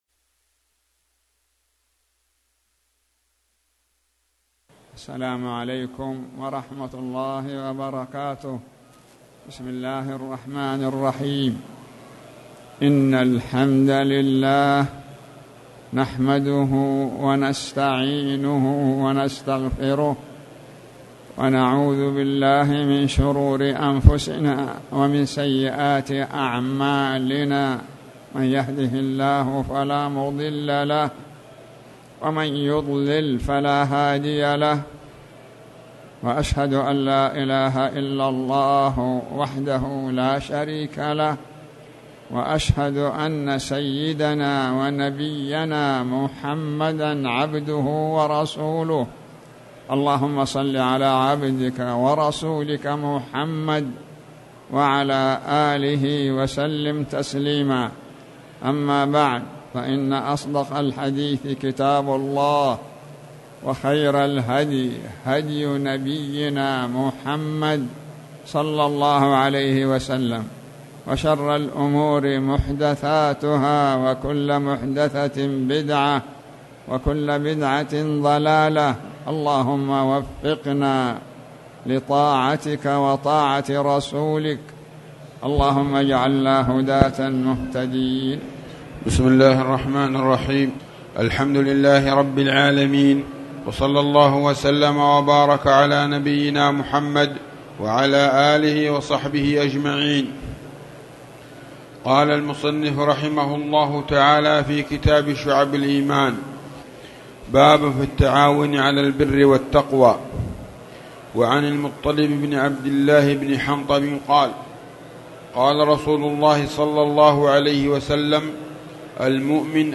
تاريخ النشر ٧ ذو القعدة ١٤٣٨ هـ المكان: المسجد الحرام الشيخ